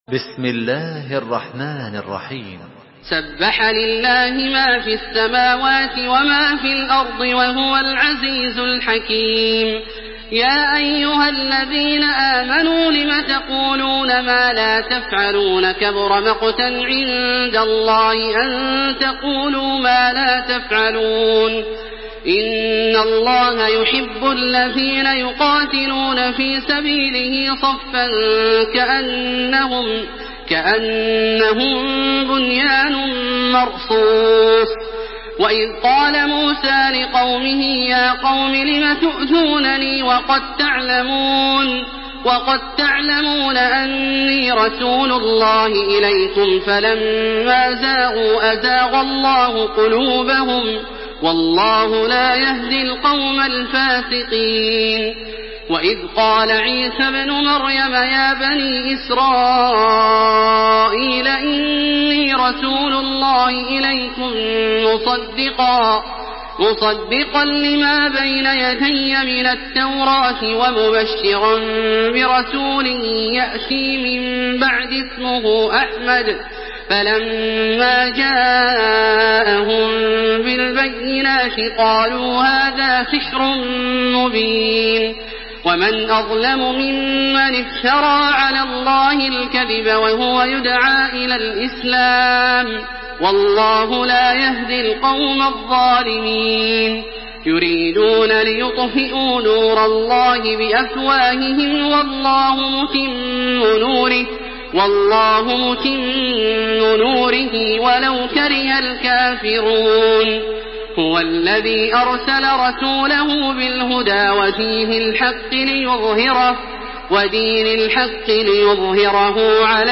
Surah As-Saf MP3 by Makkah Taraweeh 1427 in Hafs An Asim narration.
Murattal